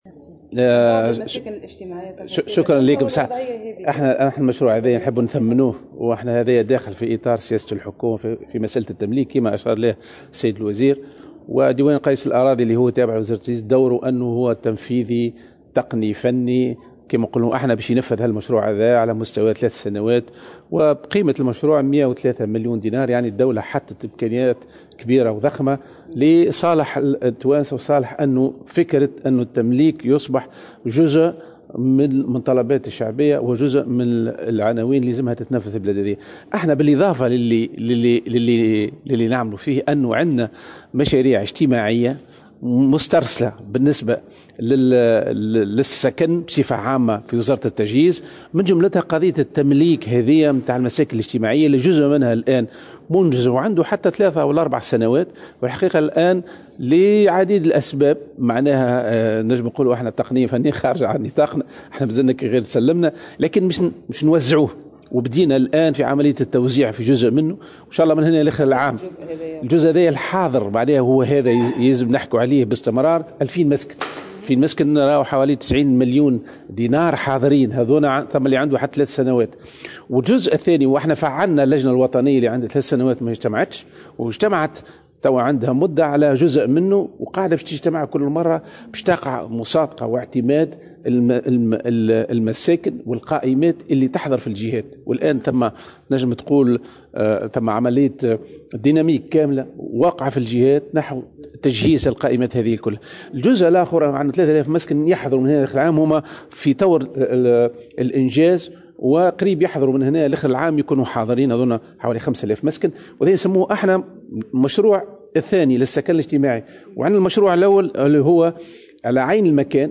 أكّد وزير التجهيز والإسكان والتهيئة الترابية منصف السليطي، في ندوة صحفية اليوم الاثنين حول مشروع الحكومة لـ"تمليك" المساكن، أن عددا من المساكن الاجتماعية أصبحت جاهزة للتسليم.